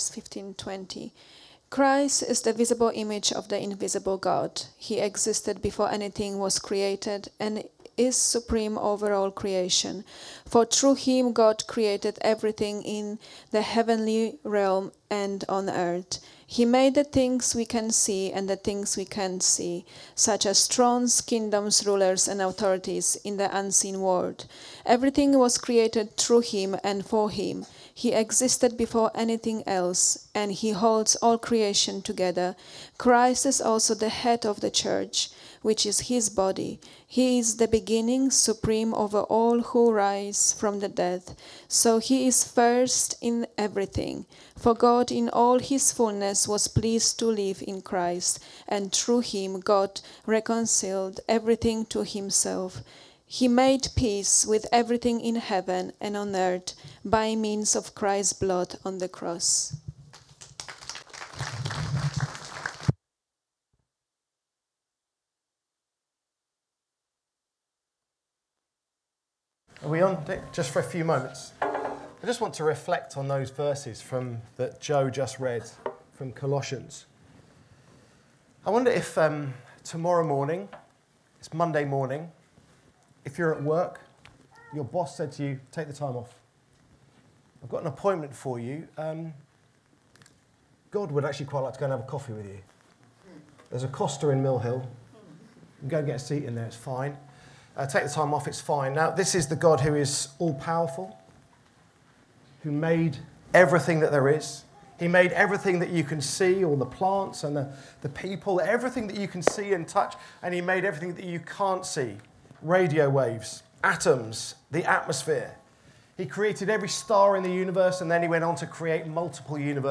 Download Christmas Carol Service Talk 2024 | Sermons at Trinity Church